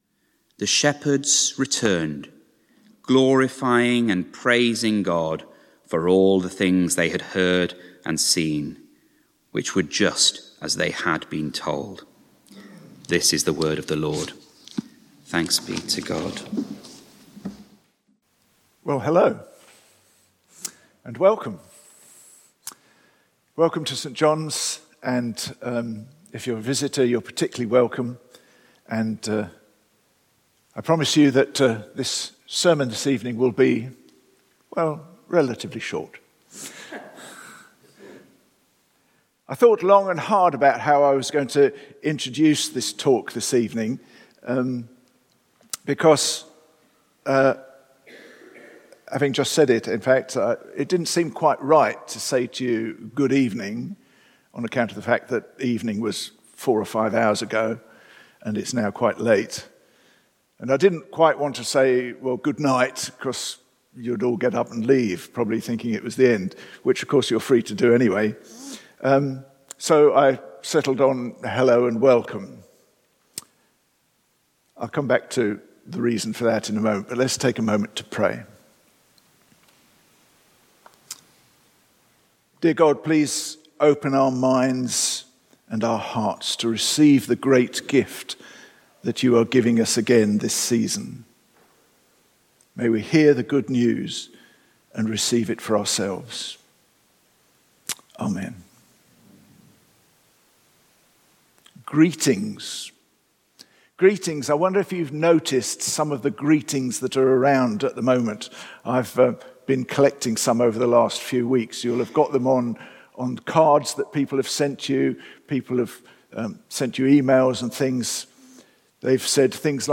From Service: "Special service"